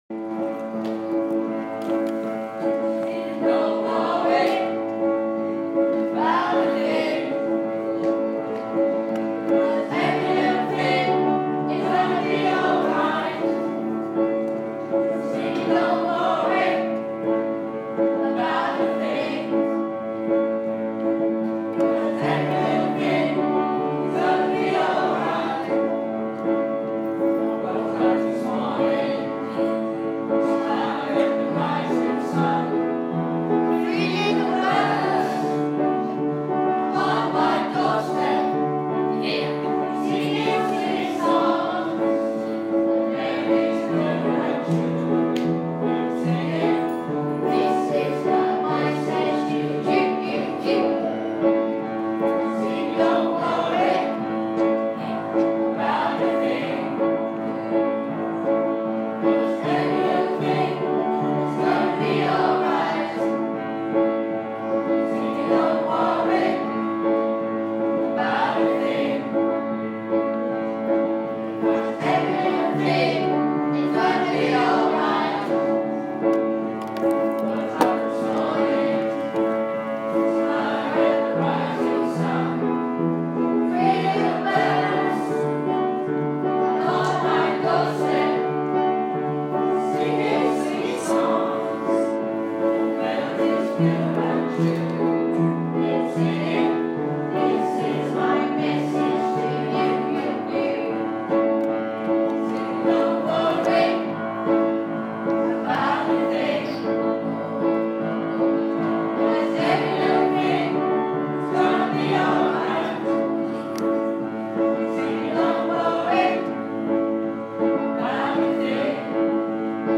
Lansdowne School Choir